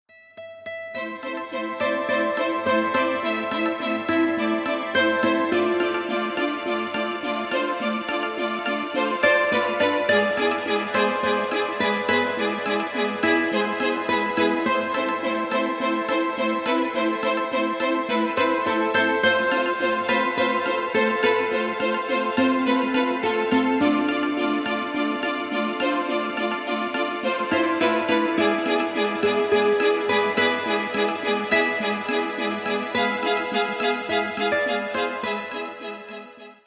спокойные
без слов
Спокойная музыка, можно на будильник